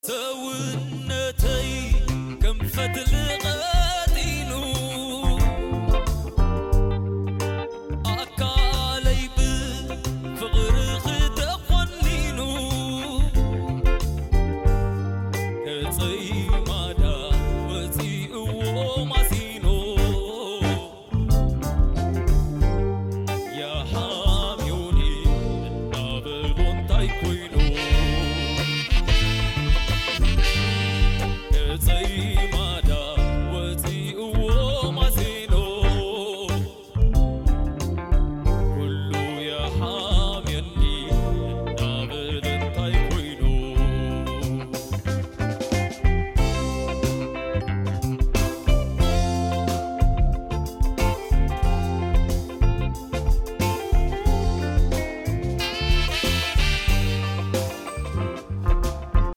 Young artists brought his classics back to life.
This wasn’t just a concert— It was a cultural revival.